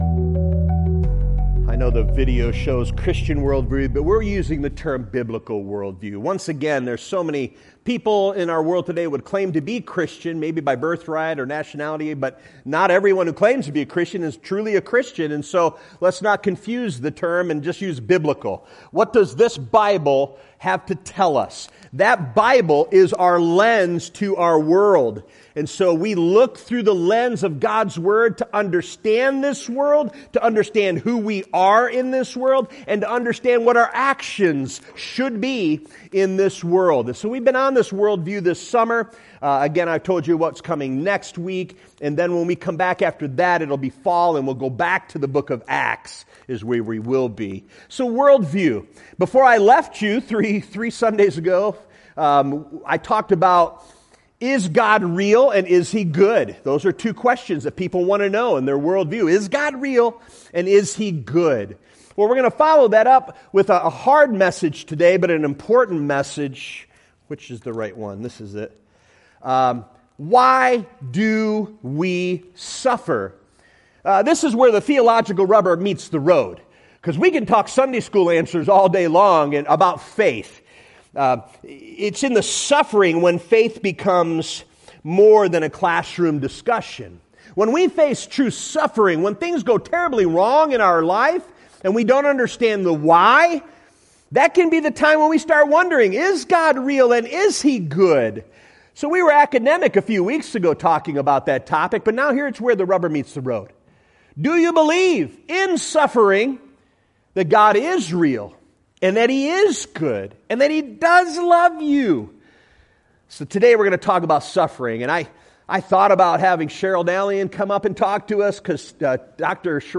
In this comforting and inspiring sermon, we learn that understanding more about God is key to enduring sufferring.